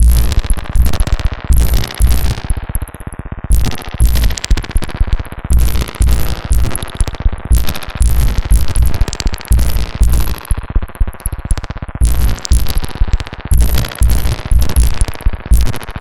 Abstract Rhythm 27.wav